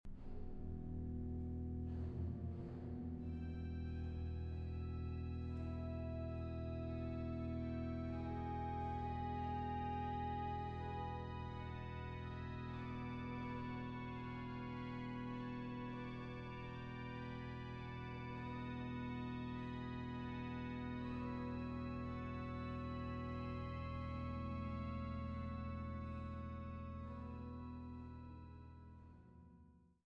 Die Orgeln im St. Petri Dom zu Bremen
Orgel